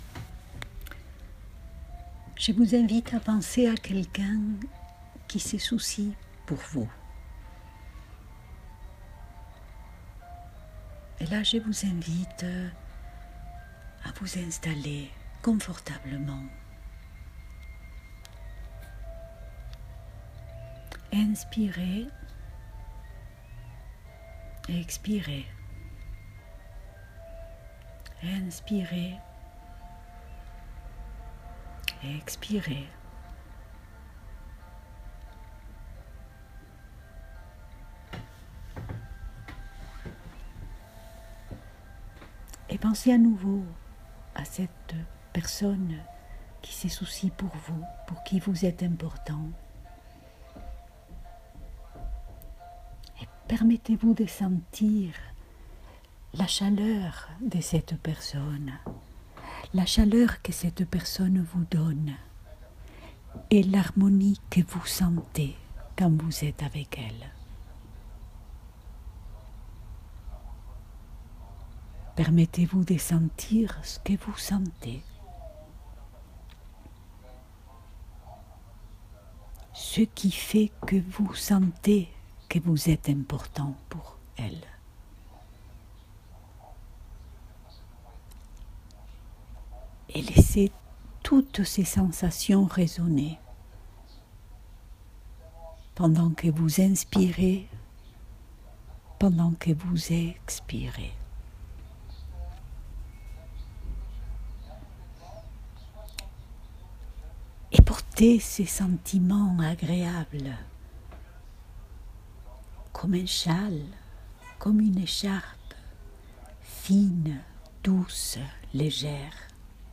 J’ai enregistré quelques méditations guidées pour mes patients; mais j’invite tous ceux qui le souhaitent à s’en servir.